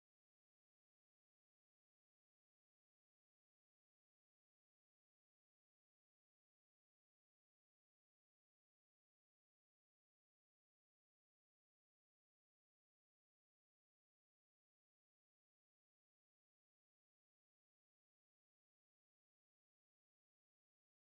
Hawksbill turtle and the rhythmic sound effects free download
Hawksbill turtle and the rhythmic pattern of raindrops